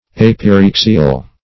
Search Result for " apyrexial" : The Collaborative International Dictionary of English v.0.48: Apyrexial \Ap`y*rex"i*al\, a. (Med.) Relating to apyrexy.